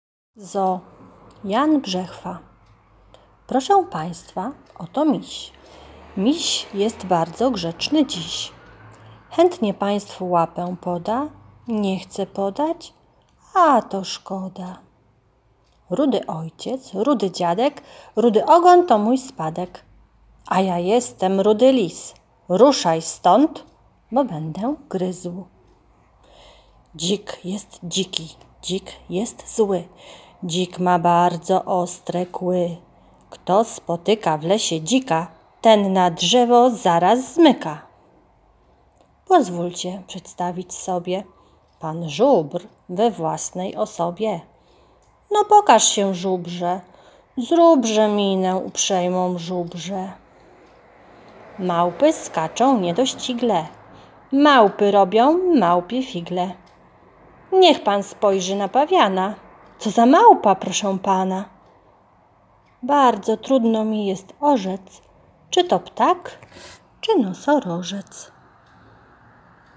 Wiersze